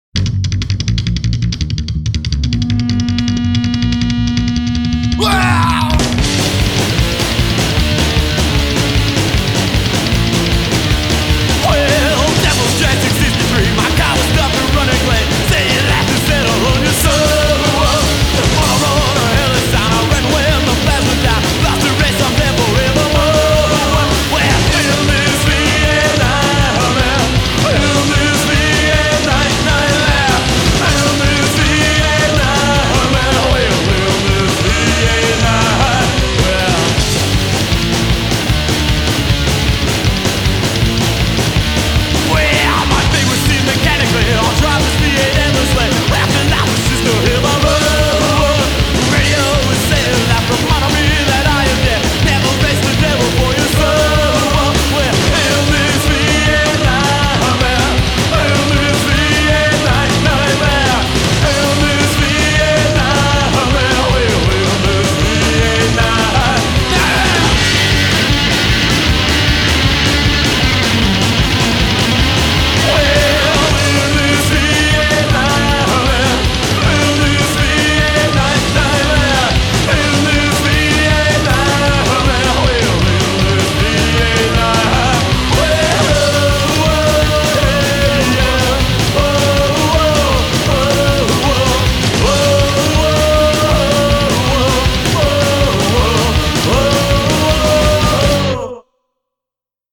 A great album from Detroit Psychobilly band.
The sound is good & musicianship is good.